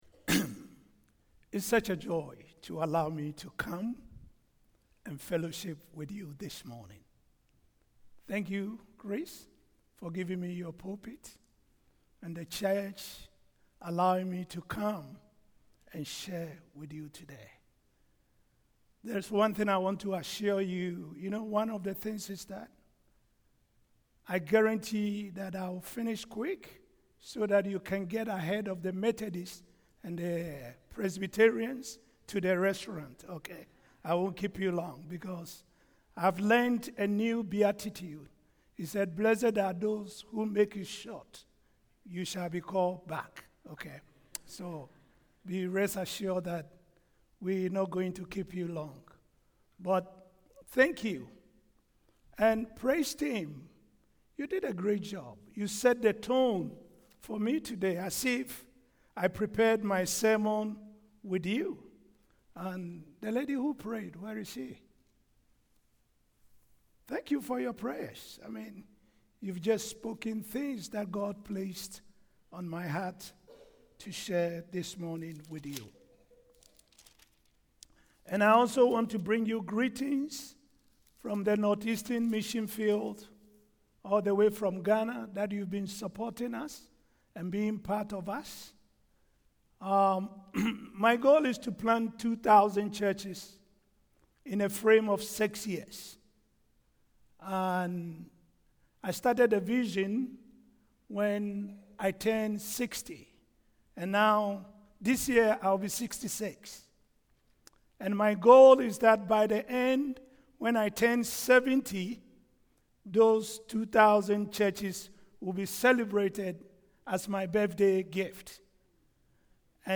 Sermons , Sunday Mornings https